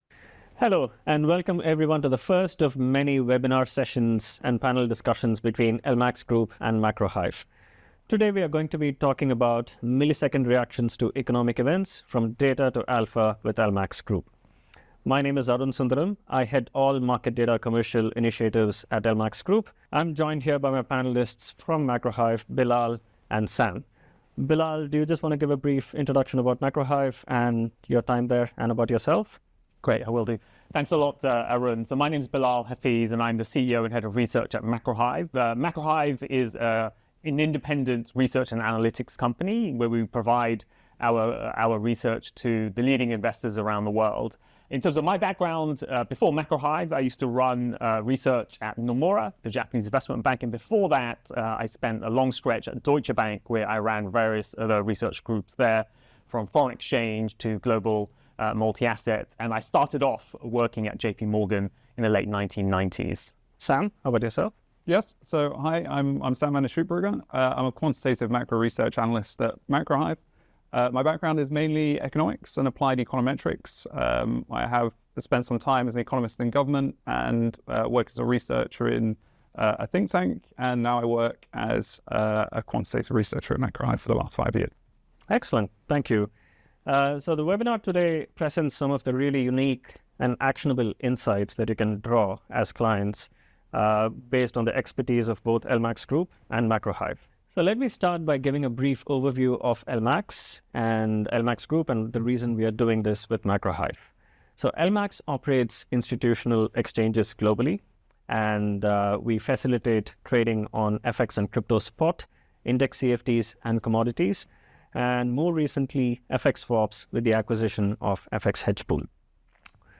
Webinar Minutes